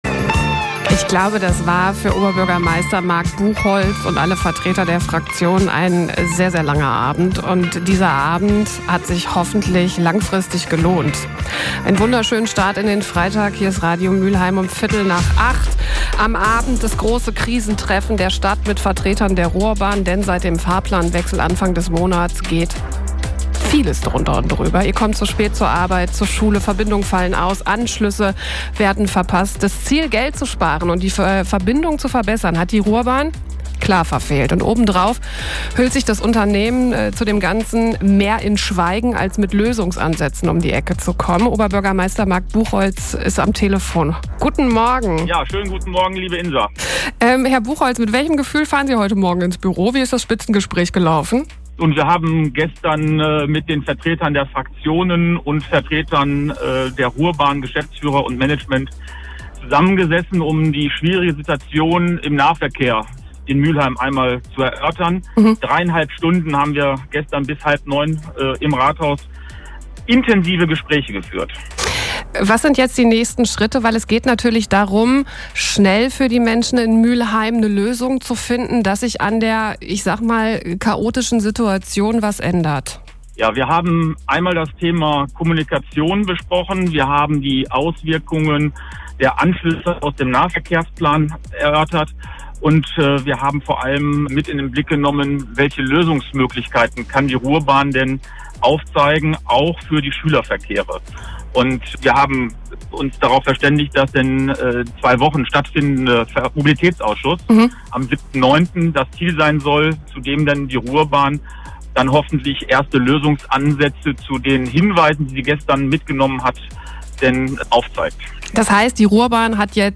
iv-marc-buchholz-.mp3